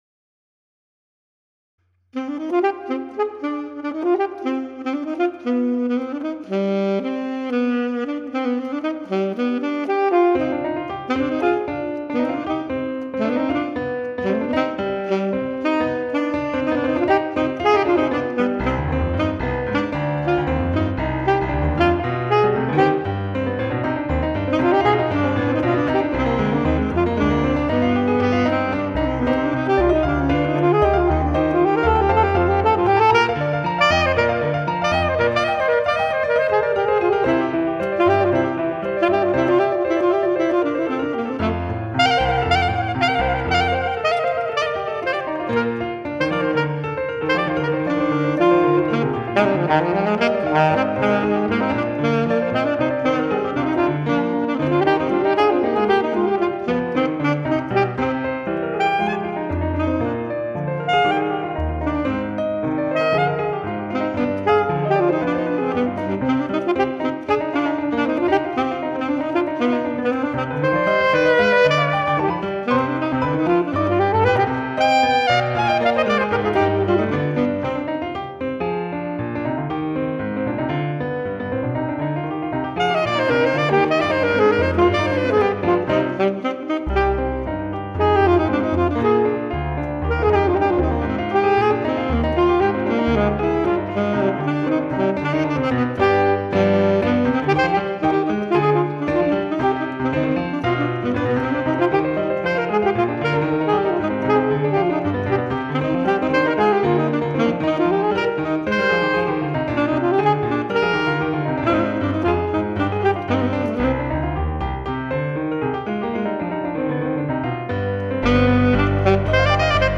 4. A short coda leads the work to its dramatic conclusion.